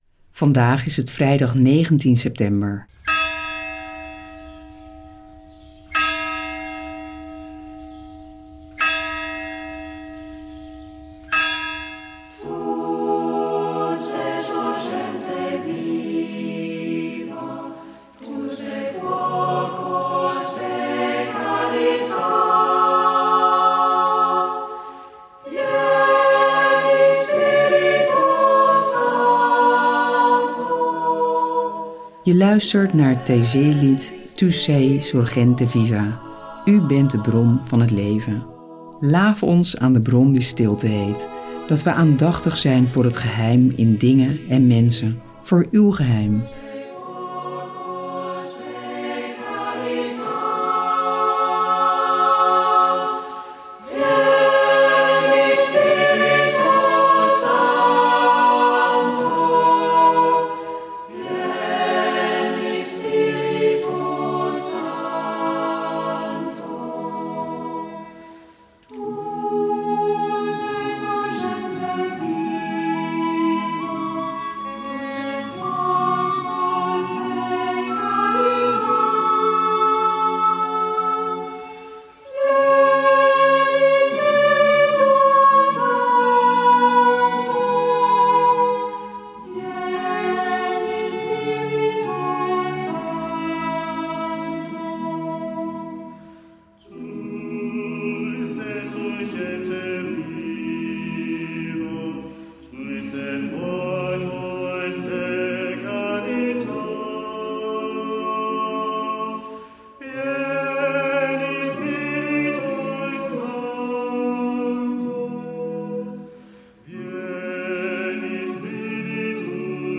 De muzikale omlijsting, overwegingen y begeleidende vragen helpen je om tot gebed te komen.